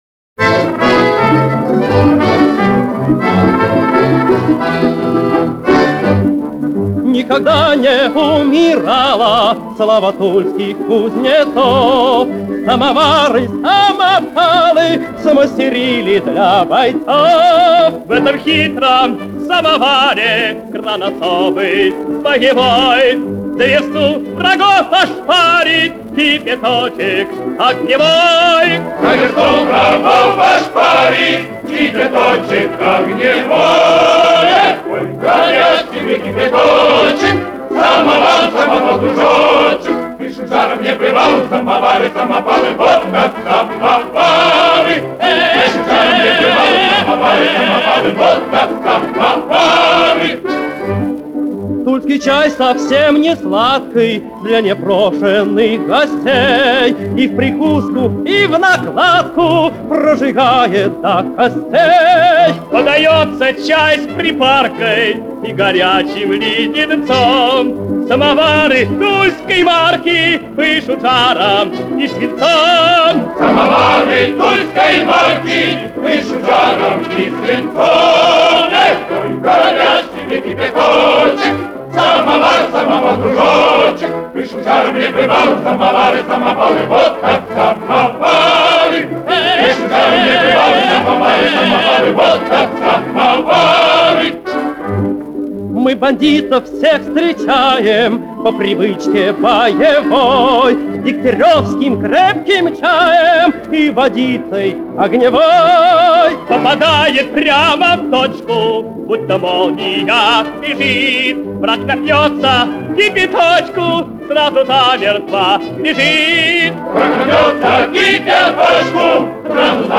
Эта запись была сделана в первые дни войны.